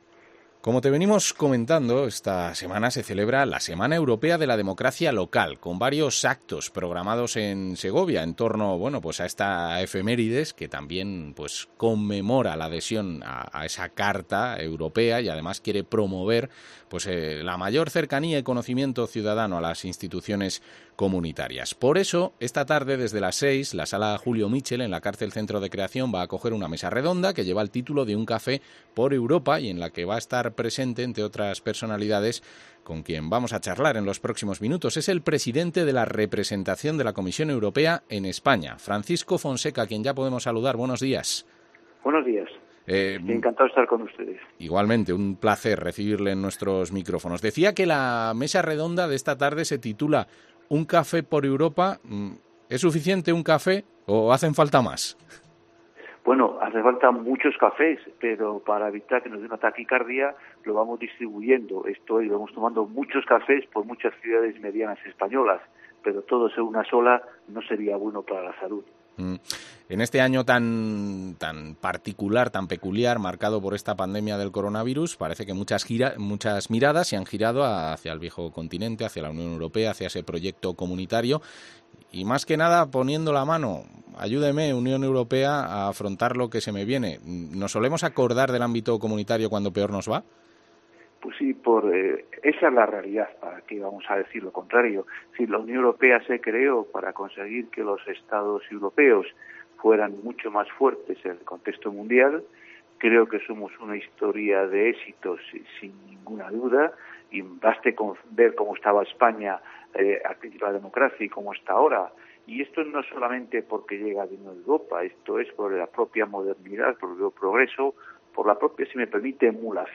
Entrevista al director de la Representación de la Comisión Europea en España, Francisco Fonseca